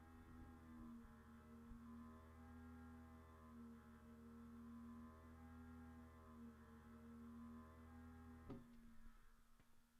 JKeq6z1Xhid_bruit-ordi.wav